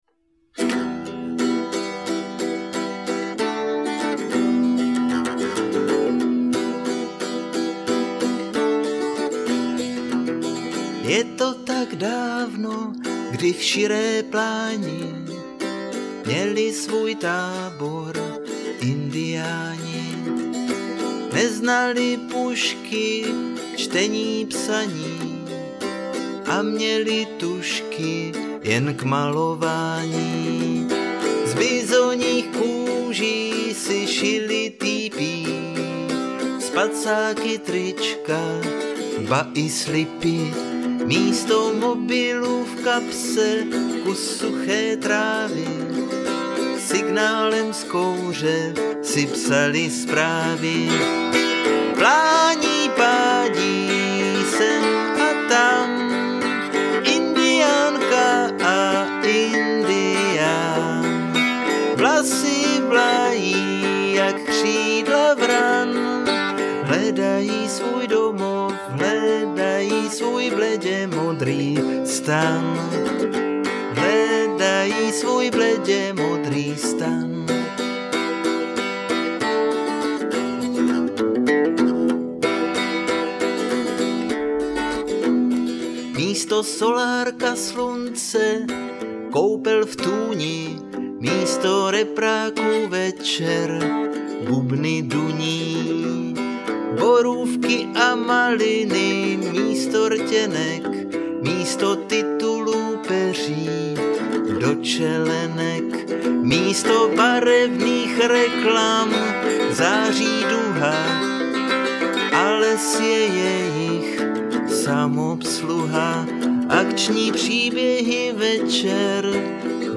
prvn� demonahr�vka